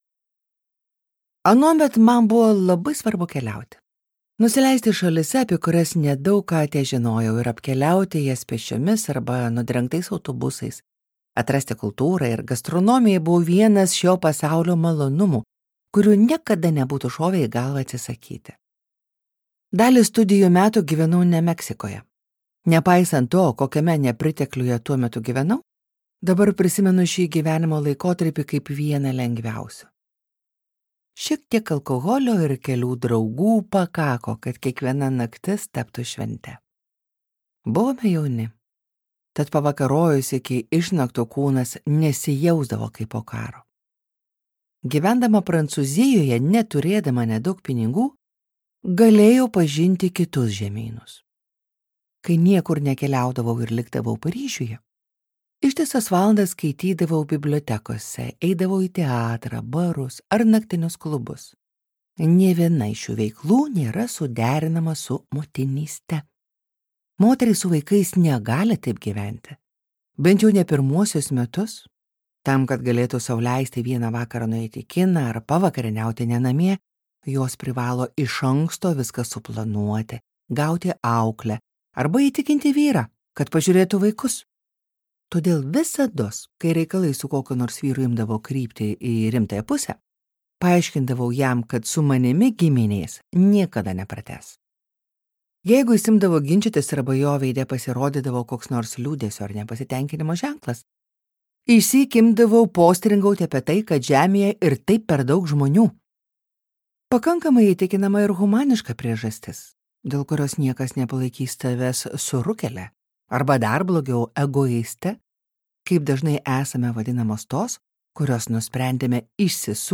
Vienintelė duktė | Audioknygos | baltos lankos